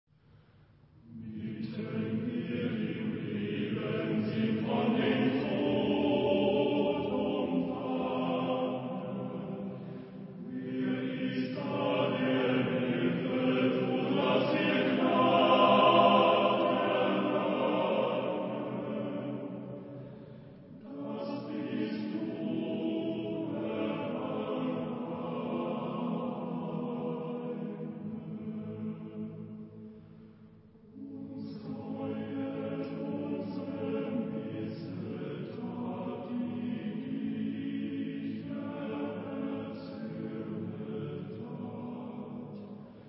Genre-Style-Forme : Sacré ; Romantique ; Choral ; Motet
Caractère de la pièce : alerte
Type de choeur : TTBB  (4 voix égales d'hommes )
Tonalité : la mineur